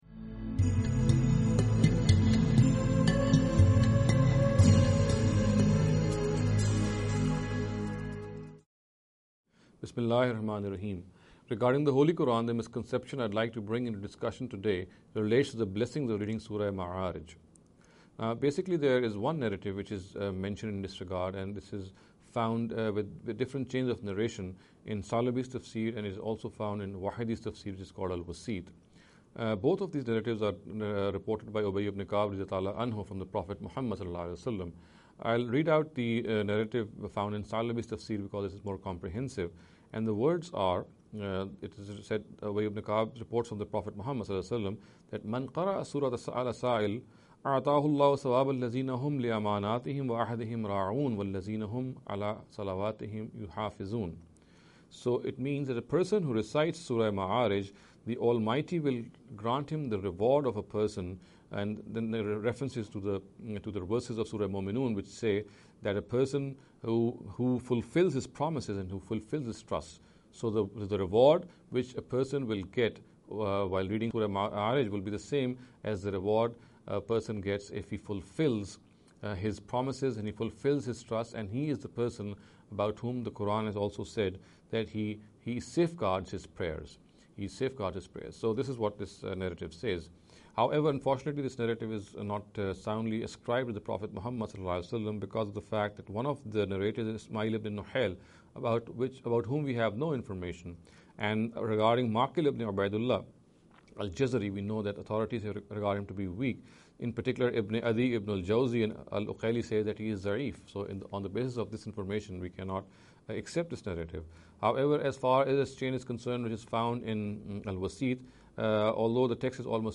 Associate Speakers